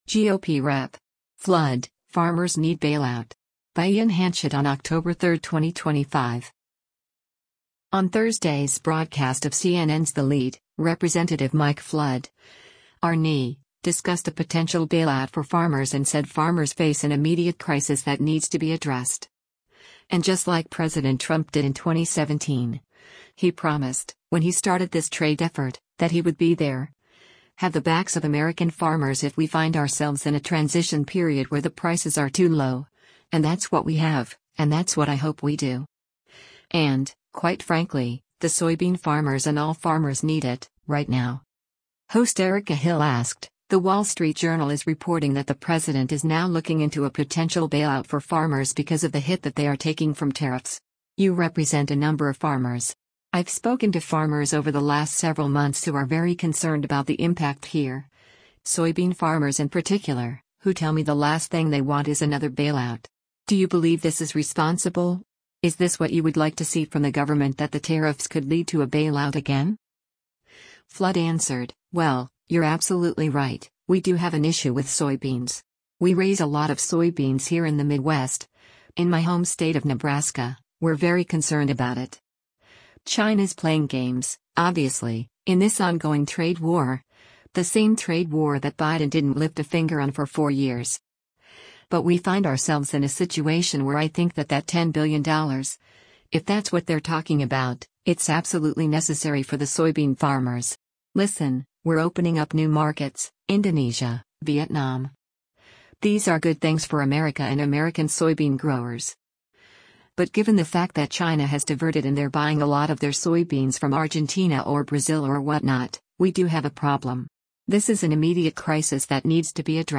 On Thursday’s broadcast of CNN’s “The Lead,” Rep. Mike Flood (R-NE) discussed a potential bailout for farmers and said farmers face “an immediate crisis that needs to be addressed.